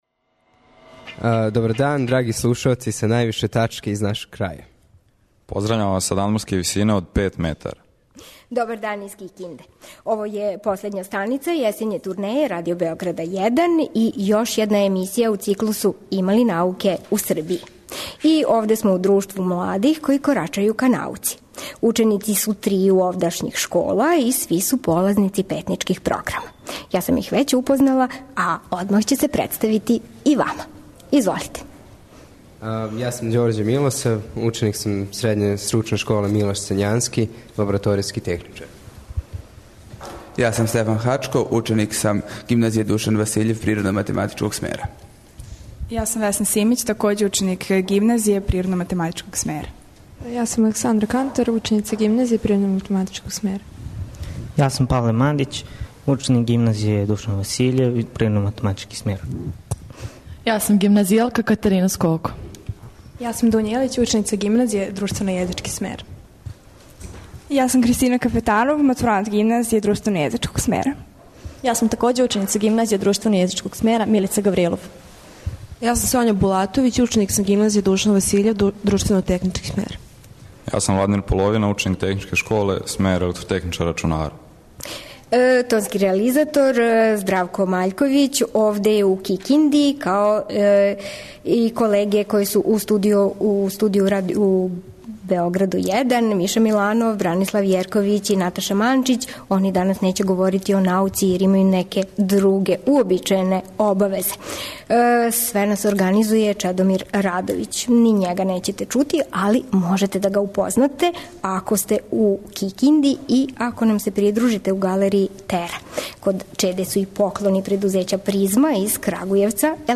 У 'Кораку ка науци' и даље постављамо питање: има ли науке у Србији? Овога пута, емисију емитујемо из Кикинде. Наши гости биће млади полазници Истраживачке станице Петница.